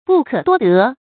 不可多得 bù kě duō dé
不可多得发音
成语正音得，不能读作“dè”。